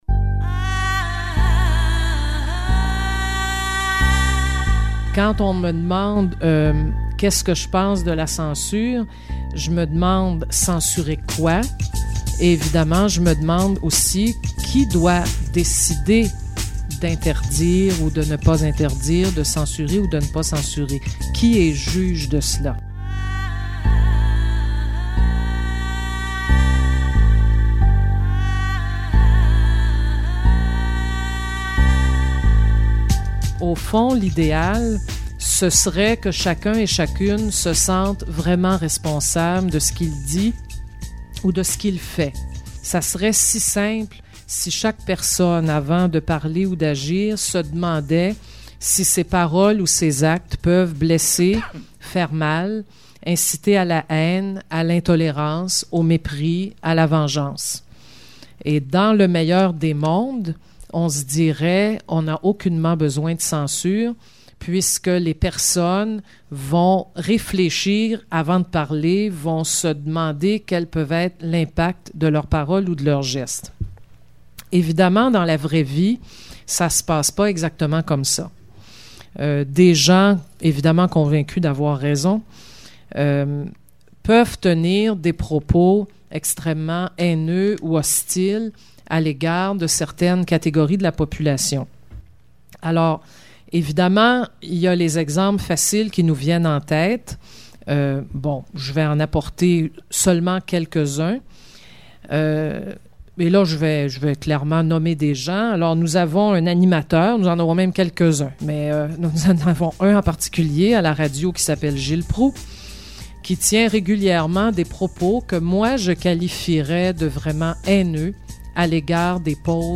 Reportage
Tout ça suivi d�un vox populi.